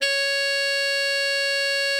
bari_sax_073.wav